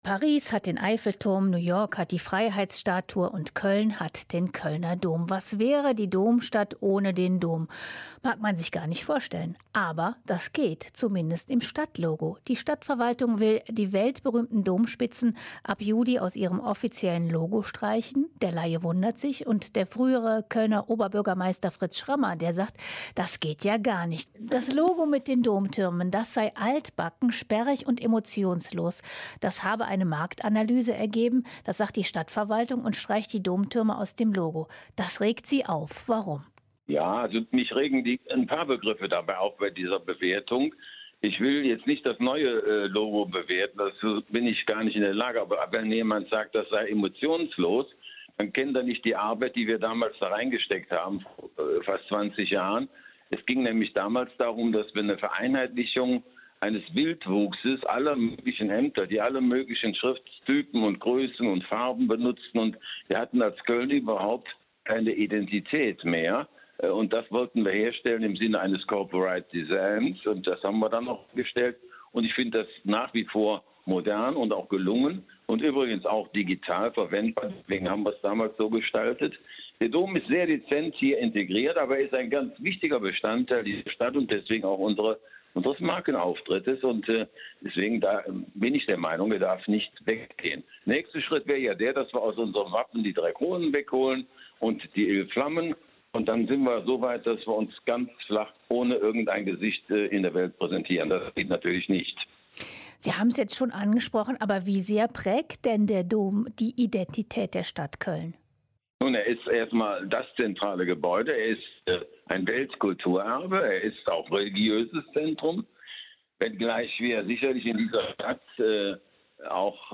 Ein Interview mit Fritz Schramma (ehemaliger Oberbürgermeister der Stadt Köln)